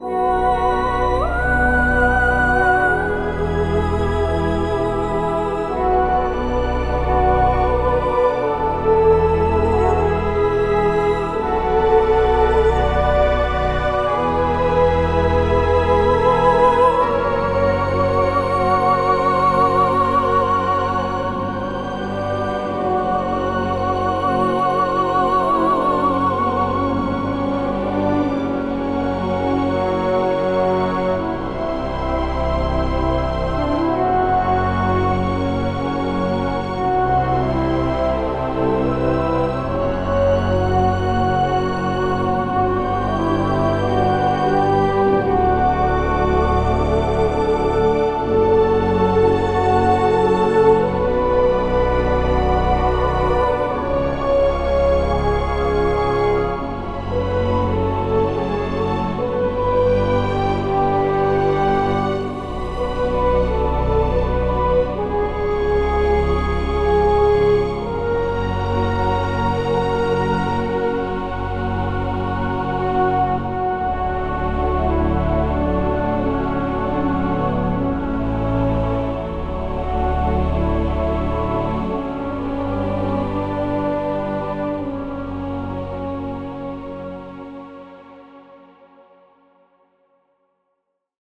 ChoirChordsBassMaster.wav